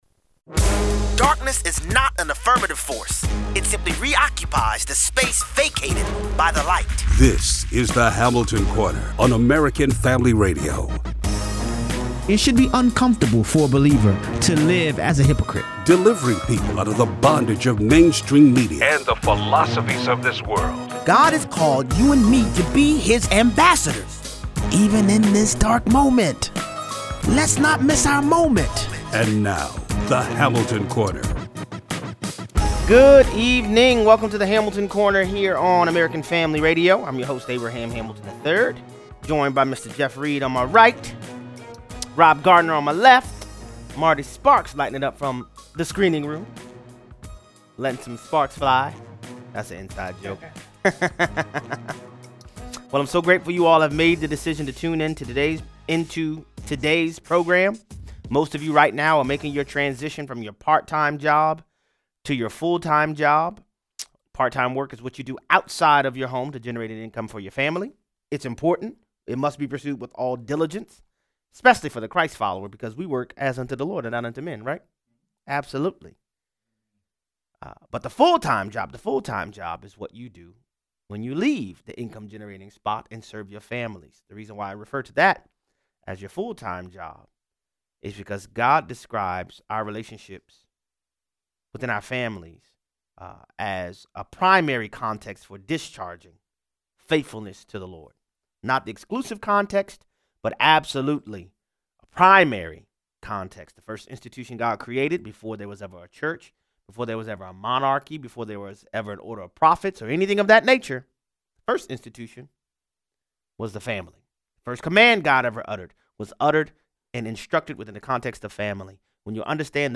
Alexandria Ocasio-Cortez reveals the true objective of socialism: control. Callers weigh in.